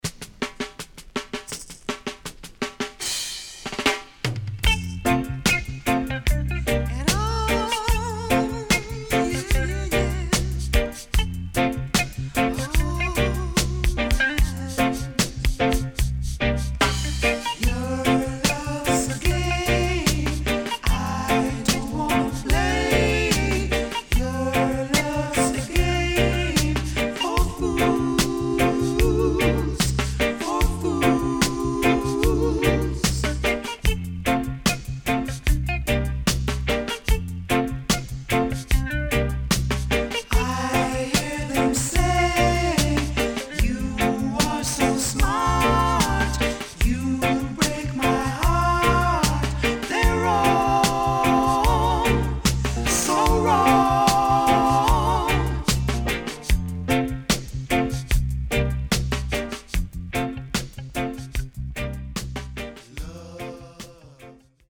HOME > DISCO45 [DANCEHALL]